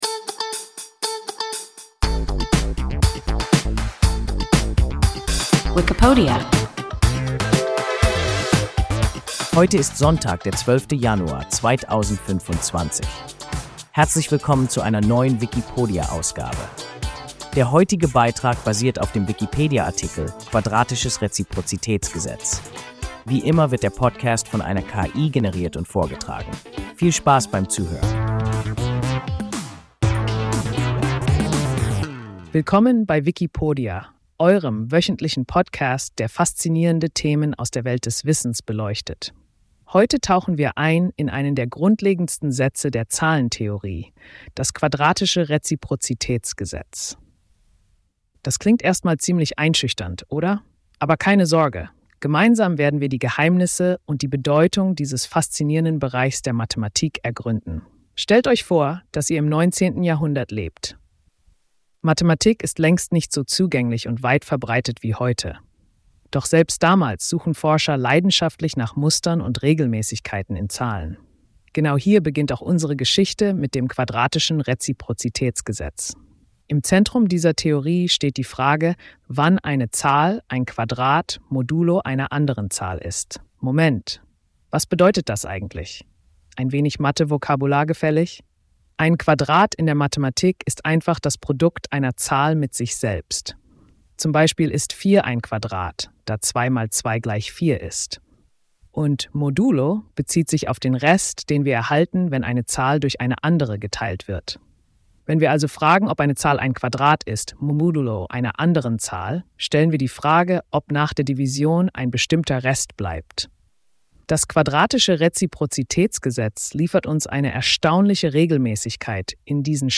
Quadratisches Reziprozitätsgesetz – WIKIPODIA – ein KI Podcast